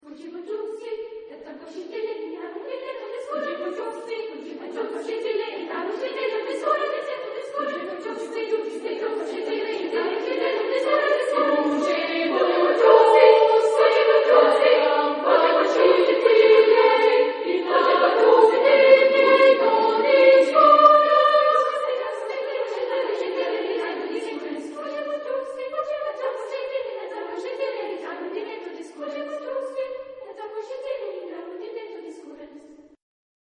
Epoque: 20th century  (1980-1989)
Genre-Style-Form: Sacred ; Prayer
Type of Choir: SSAA  (4 women voices )
Tonality: D dorian